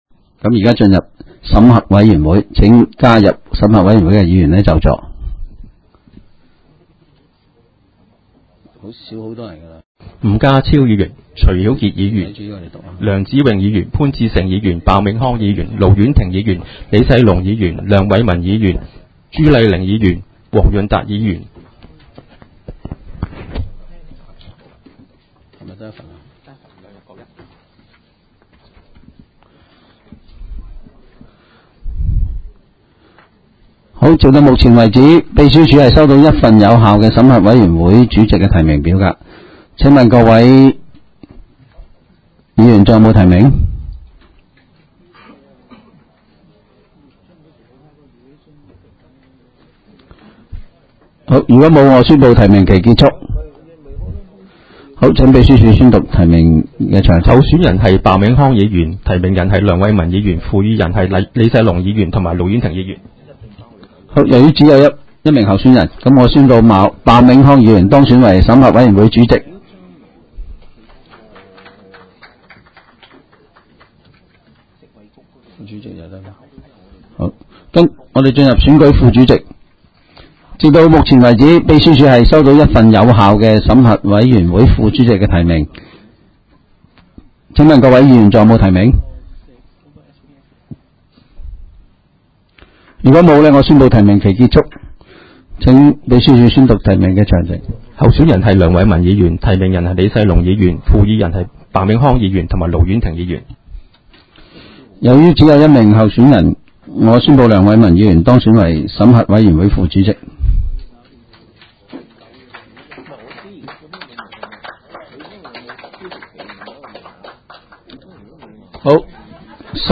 委员会会议的录音记录
审核委员会第一次特别会议会议 日期: 2018-01-03 (星期三) 时间: 下午4时01分 地点: 香港葵涌兴芳路166-174号 葵兴政府合署10楼 葵青民政事务处会议室 议程 讨论时间 1 选举审核委员会主席及副主席 00:02:03 全部展开 全部收回 议程:1 选举审核委员会主席及副主席 讨论时间: 00:02:03 前一页 返回页首 如欲参阅以上文件所载档案较大的附件或受版权保护的附件，请向 区议会秘书处 或有关版权持有人（按情况）查询。